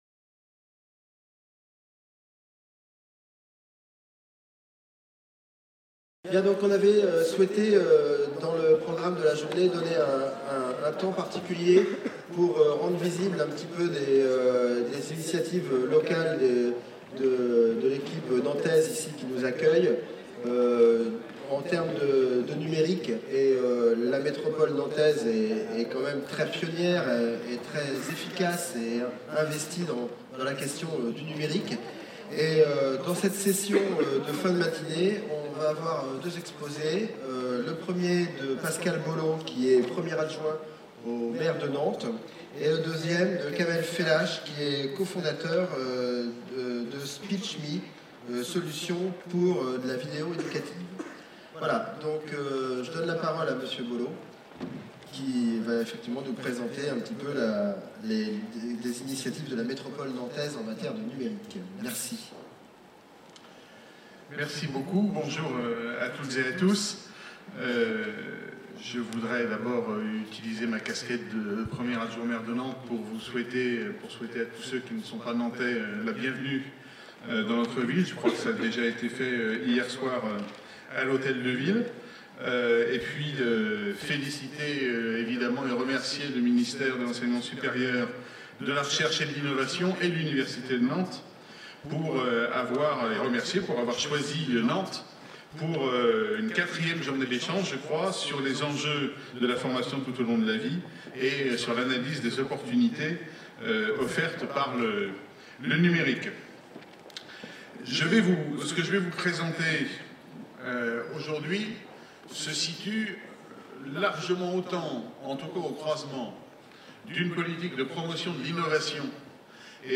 4ème rencontre : coorganisée le 5 décembre 2017, par la DGESIP et l'université de Nantes au Stéréolux (Ile de Nantes). Cycle de journées de rencontres sur les nouveaux modèles pour la F.T.L.V. 1er modèle : les effets du numérique sur l'organisation du travail, les réponses de l'enseignement supérieur.